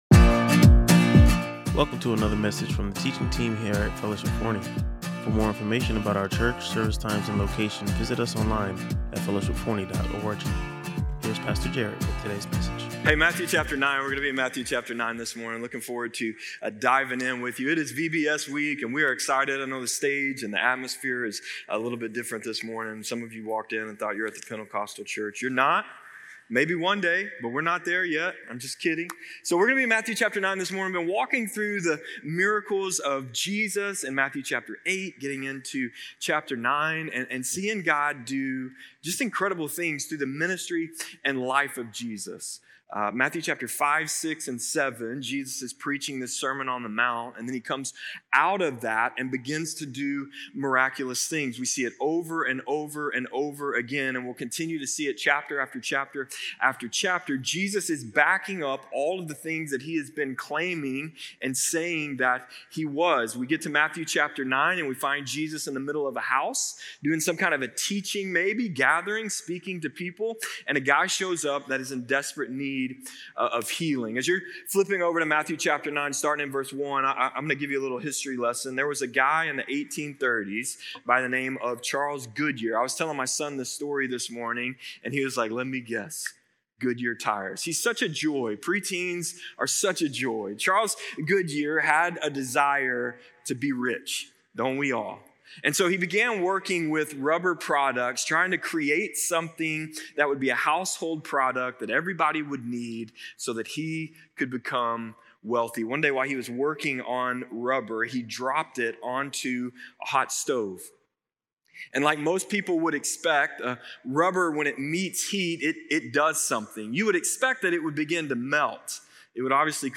The message challenged believers to consider whether their faith is based solely on what God can do for them, or if it’s rooted in who He is. Listen to or watch the full sermon and discover how God might be working in your life beyond your immediate circumstances.